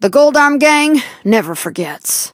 belle_die_vo_01.ogg